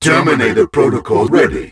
Not bad, sounds are smooth as you'd expect Smile